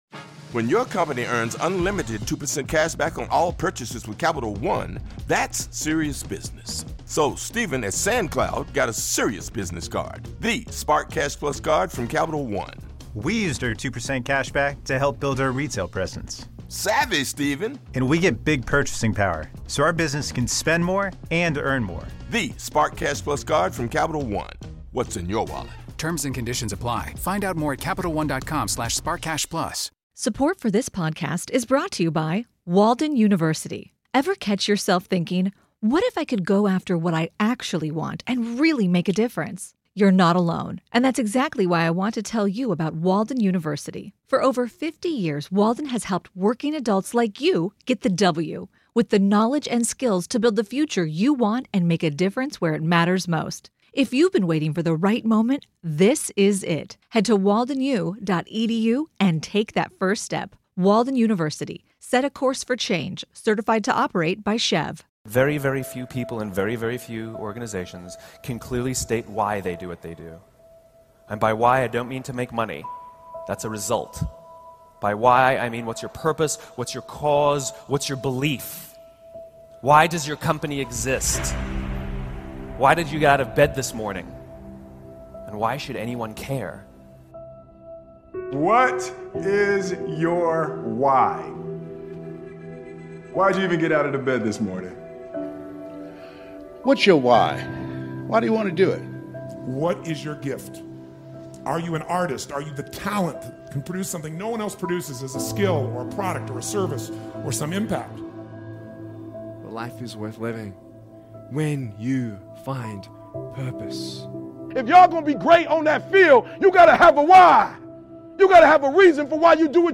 I hope this speech will inspire you and motivate you to figure out what you should go after and why!